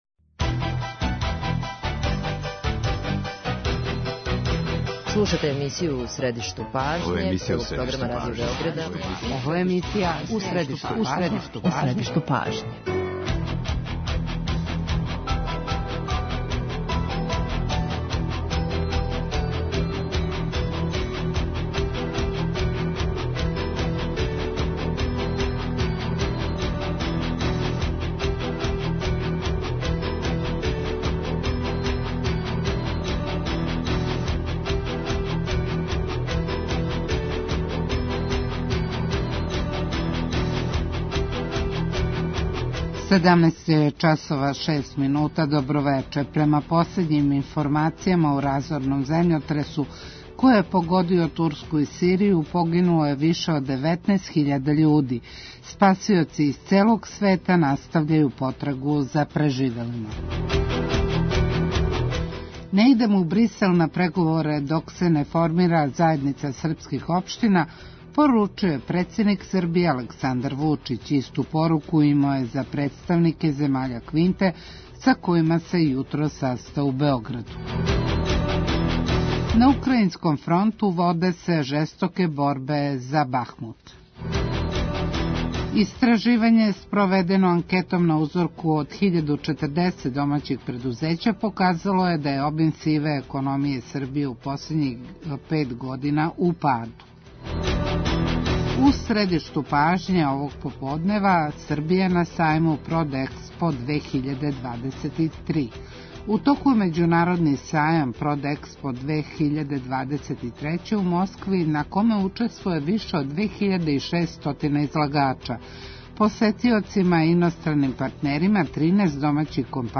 Чучемо и искуства неколико наших компанија које се представљају на сајму.